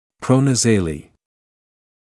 [ˌprɔnæ’zeɪliː][ˌпронэ’зэйли:]проназале; самая передняя точка носа (цефалометрический ориентир)